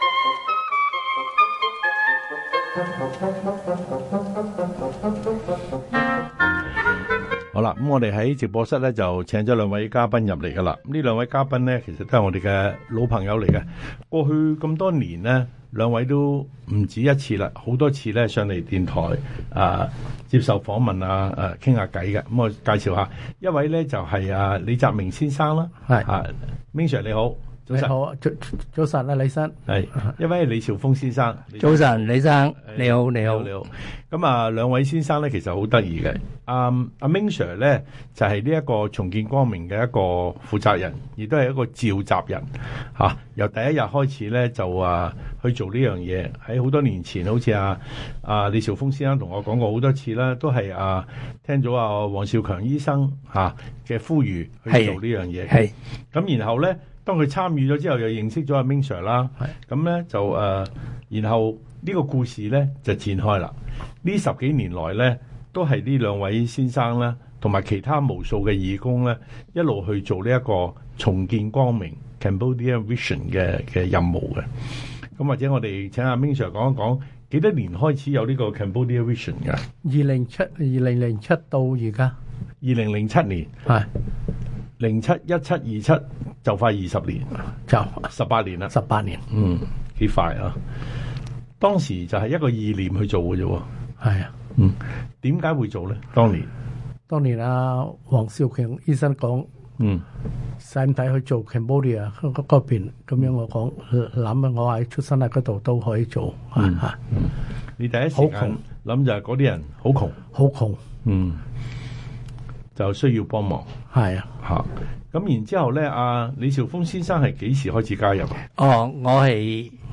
以下為廣東話訪問錄音：